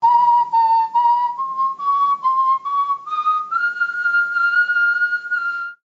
Panflute Whistle
Panflute Whistle is a free sfx sound effect available for download in MP3 format.
yt_BYAa6jotZqc_panflute_whistle.mp3